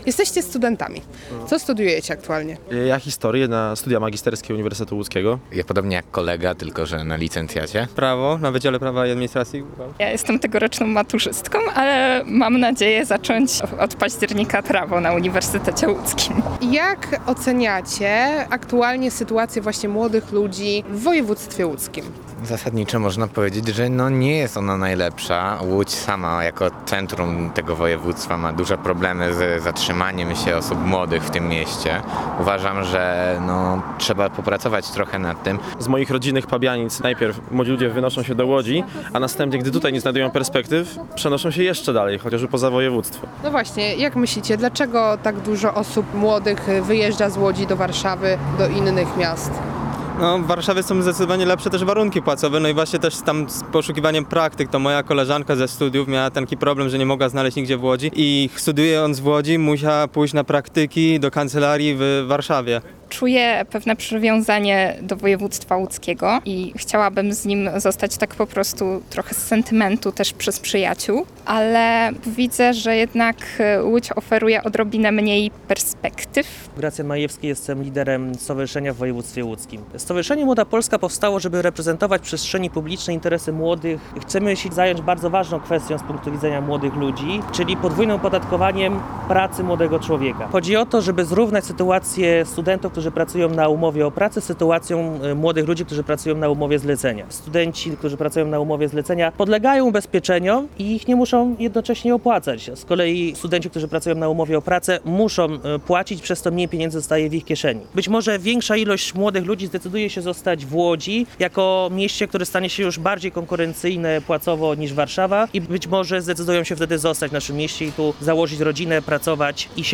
Zapytaliśmy młodzież, jak ocenia swoje możliwości na tutejszym rynku pracy.